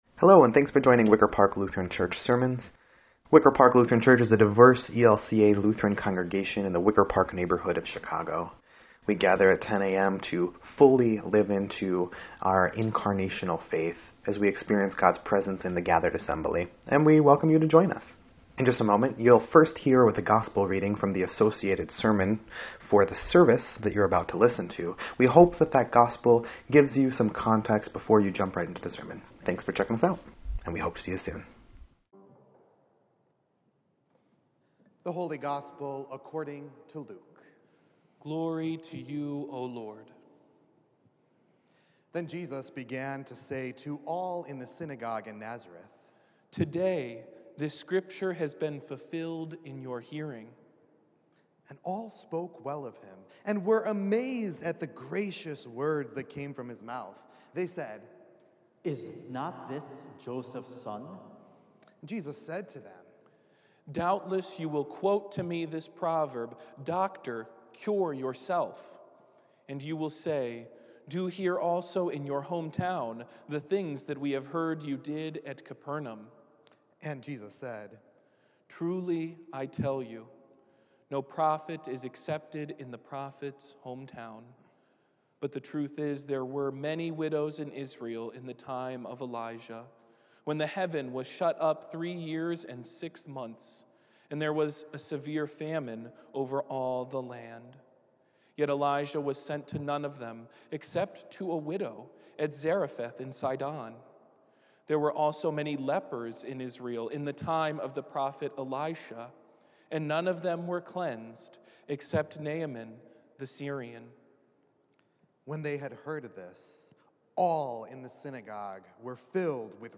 1.30.22-Sermon_EDIT.mp3